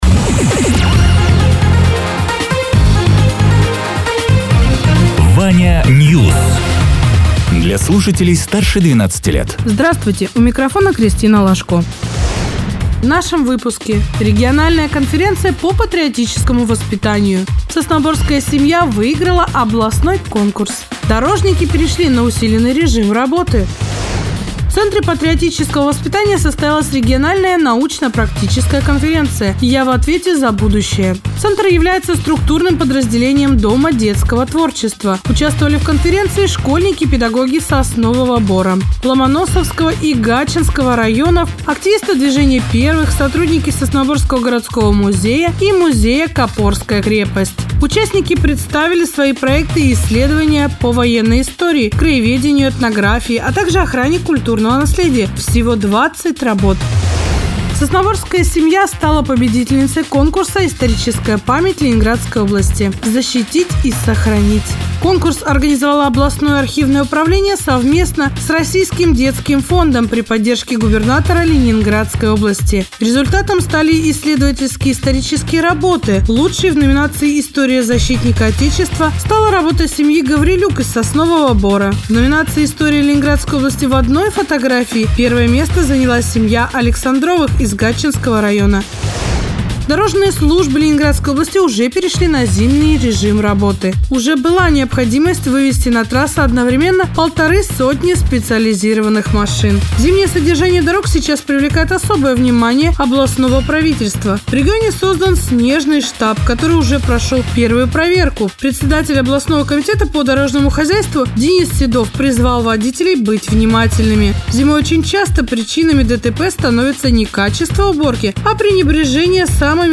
Радио ТЕРА 19.11.2024_12.00_Новости_Соснового_Бора